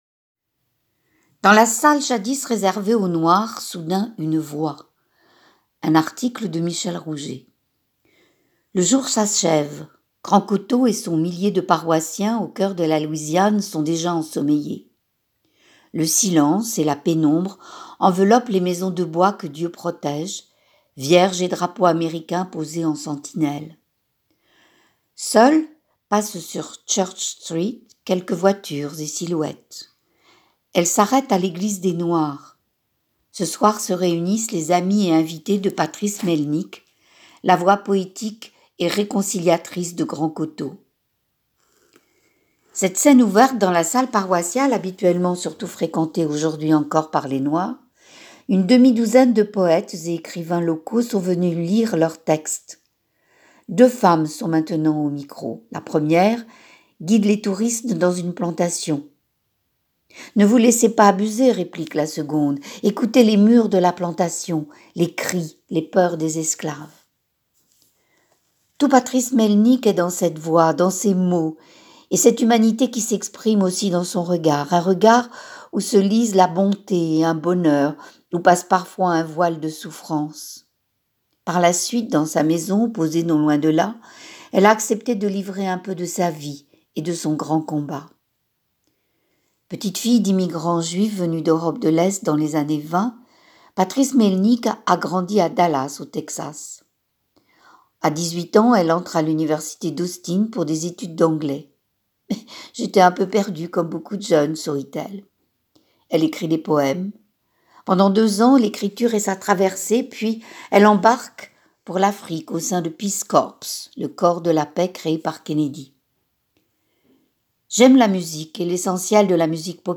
C'est « Scène ouverte » dans la salle paroissiale habituellement surtout fréquentée, aujourd'hui encore, par les Noirs. Une demi-douzaine de poètes et écrivains locaux sont venus lire leurs textes. Deux femmes sont maintenant au micro.